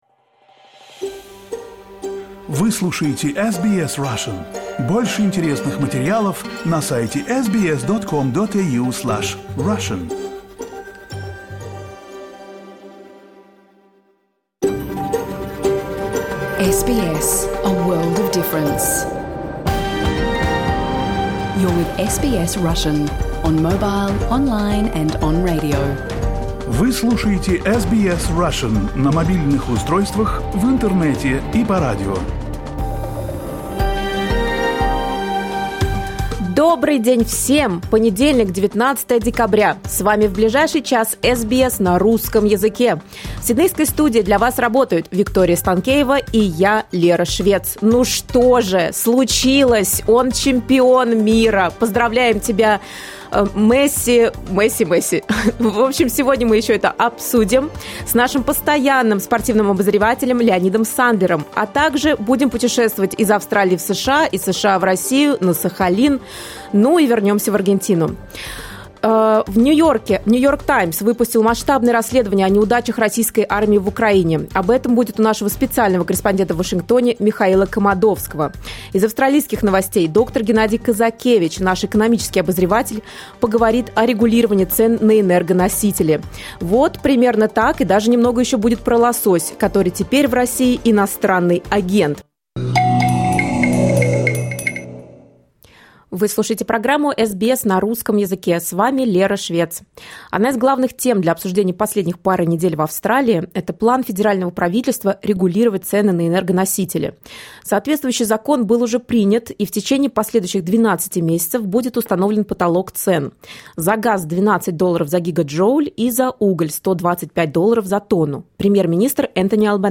You can listen to SBS Russian program live on the radio, on our website and on the SBS Radio app.